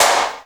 RS DANCE-CLP.wav